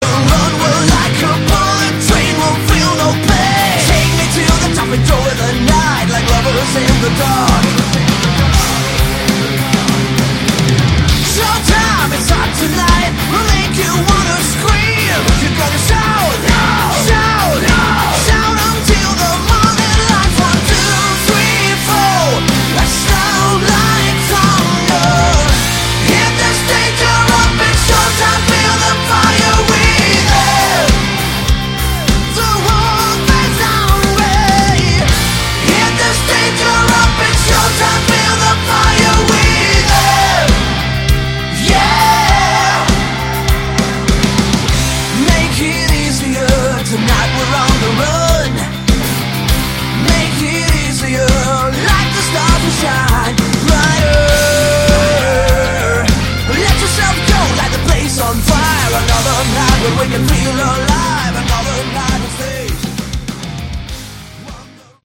Category: Hard Rock
vocals
bass
guitars
drums